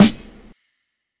Snares
Medicated Snare 28.wav